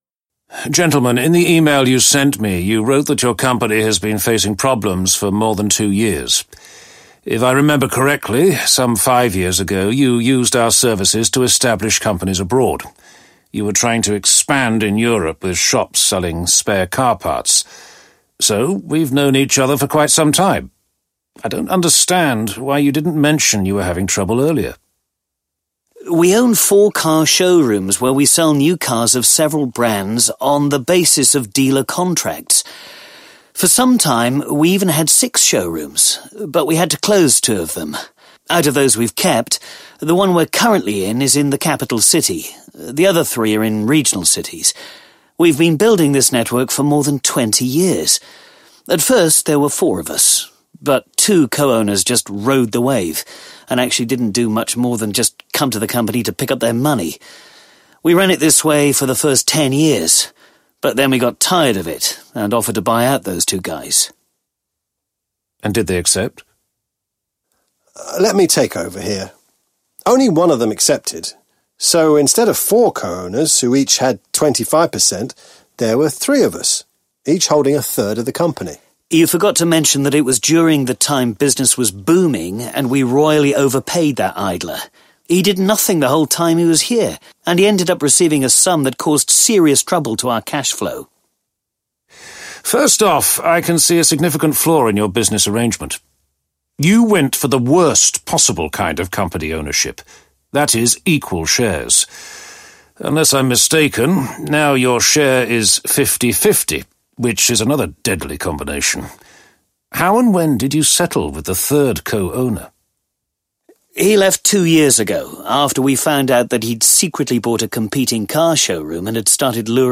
Business Risk Buster Intervenes 1 audiokniha
Ukázka z knihy